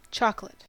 File:En-us-chocolate.ogg
English: Pronunciation of the term in US English